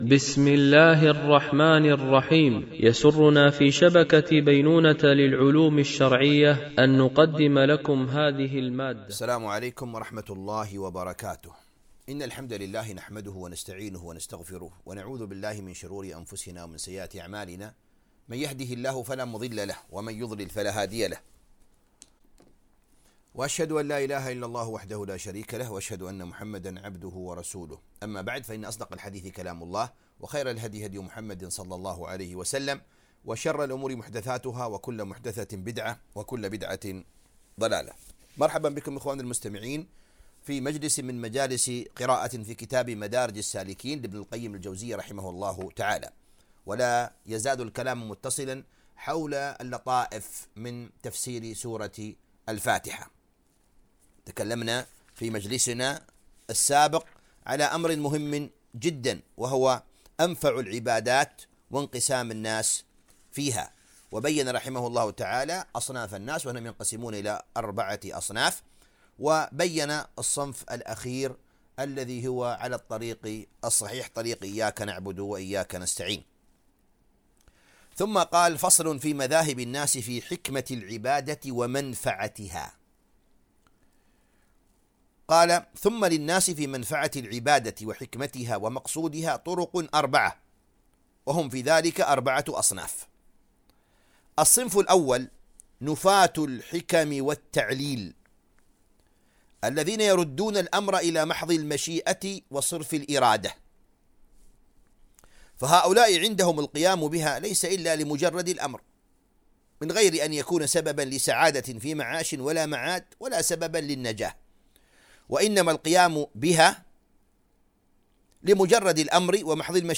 قراءة من كتاب مدارج السالكين - الدرس 13